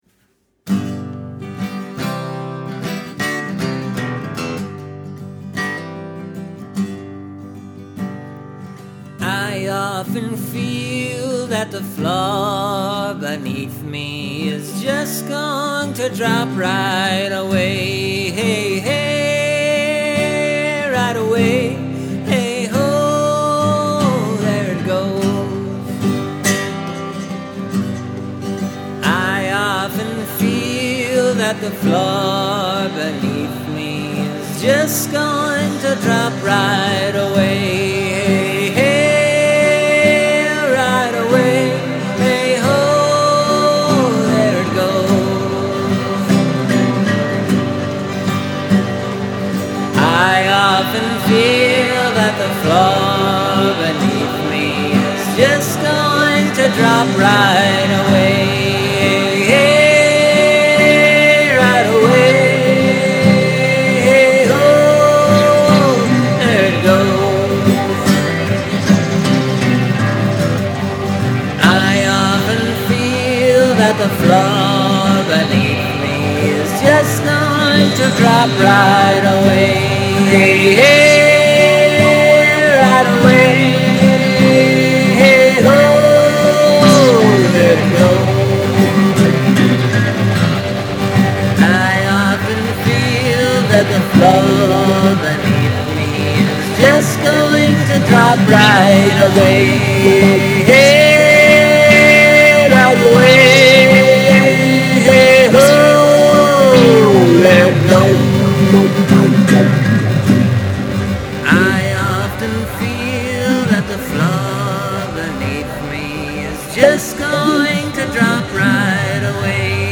Shout it!
So, I just duplicated the main guitar and vocal track a bunch of times, applied several canned effects, adjusted the volume settings, and wham-oh!
It is in three-quarter time, though.